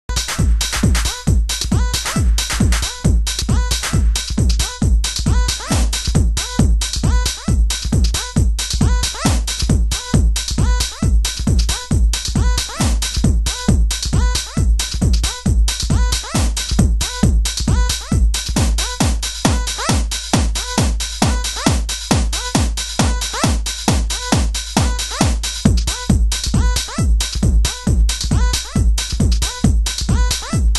○パワーで押し切るミニマルなシカゴテクノ・アルバム！